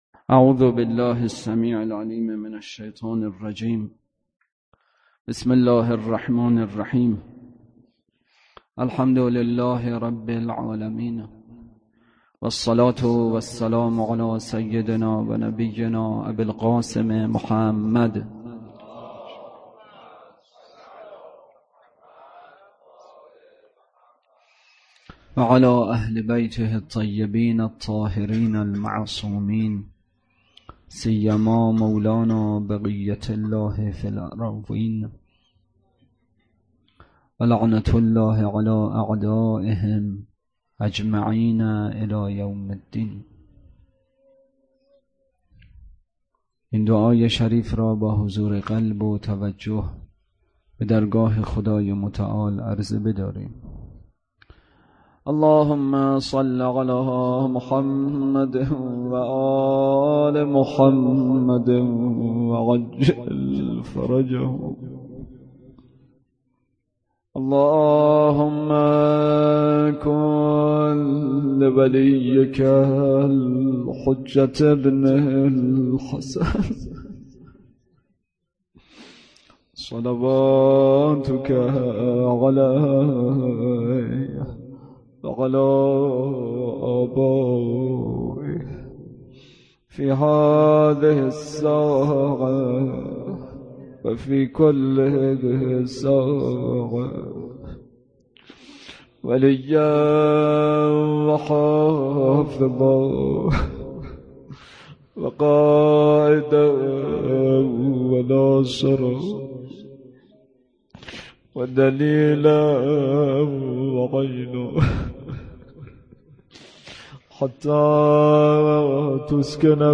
احکام: وجوب ادا خمس بحث اصلی: جمع بندی بحث قساوت قلب، بیان راه رهایی از قساوت قلب روضه: کودکان کربلا